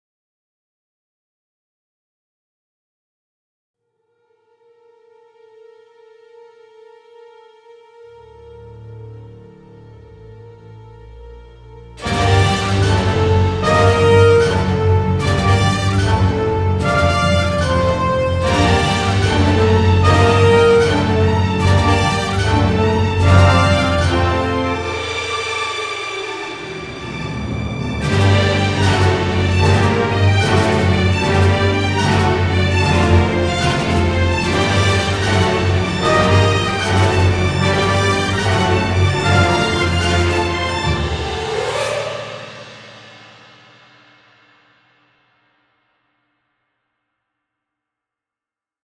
Dommage que je ne puisse pas mettre la cinématique avec car ça donnerait un sens à la musique, mais ça va pas dans la finesse (en gros c'est du bon combat bien sanglant comme ils savent faire dans les jeux vidéos). Je préviens tout de suite, c'est pas du Messiaen au niveau de la composition (4 accords à trois sons).